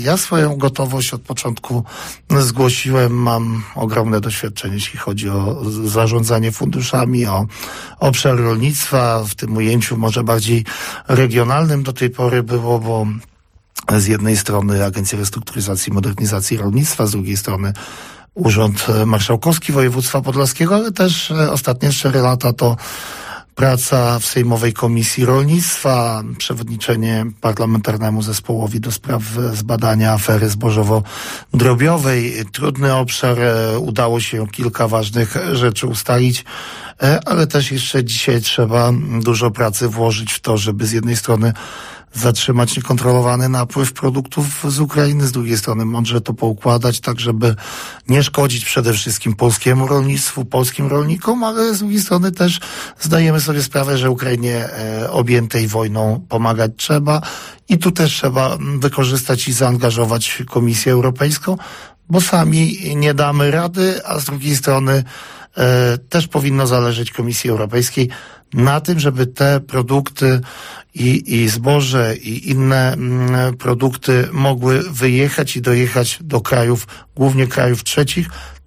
Czas pokaże. Cierpliwie czekamy. Zobaczymy jakie rozstrzygnięcia ostatecznie zapadną – mówił w audycji Gość Dnia Radia nadzieja poseł PSL-u, Stefan Krajewski o tym, czy zostanie ministrem rolnictwa w nowym rządzie.